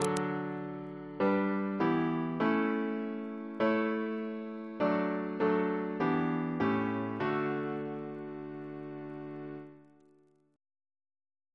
Single chant in D Composer: F. A. Gore Ouseley (1825-1889) Reference psalters: ACB: 3; ACP: 126; CWP: 236; H1982: S421; OCB: 3; PP/SNCB: 18; RSCM: 202